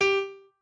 pianoadrib1_17.ogg